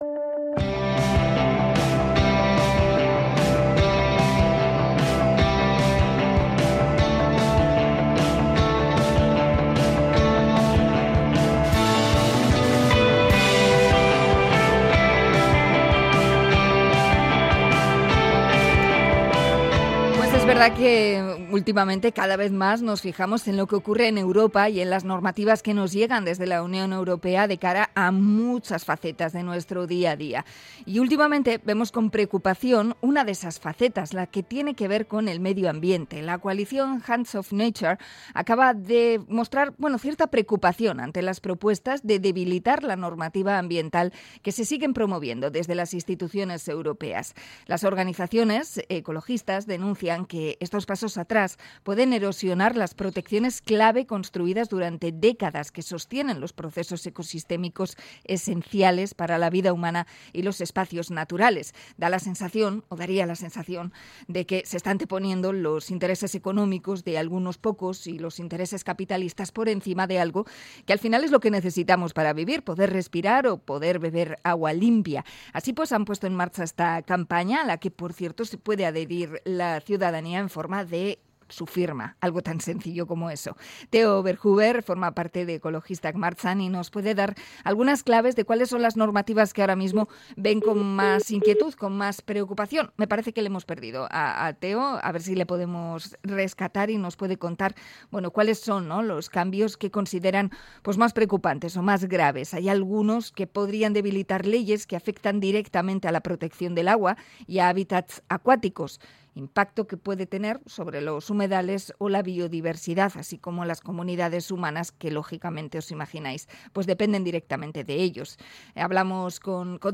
Entrevista por la campaña Hands off Nature